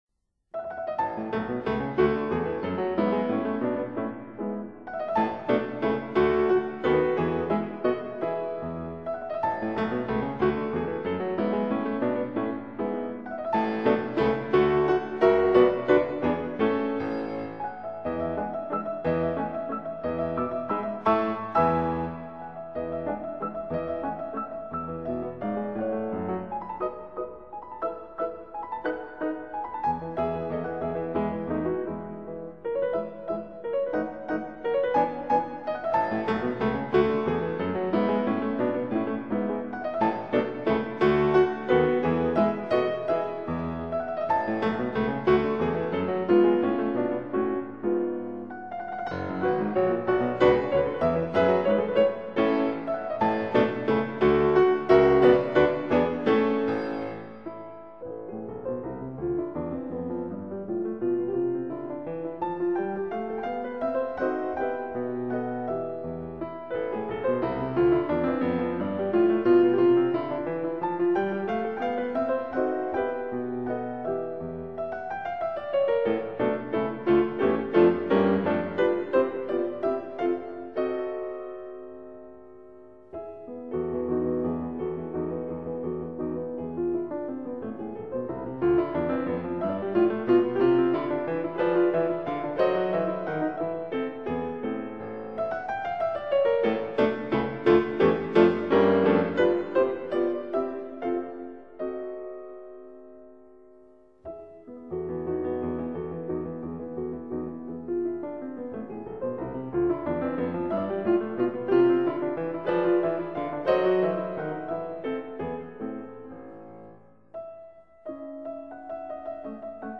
當時做為鋼琴演奏家的他聲望很高，是鋼琴演奏從古典風格過渡到浪漫主義風格的橋樑，